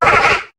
Cri de Chuchmur dans Pokémon HOME.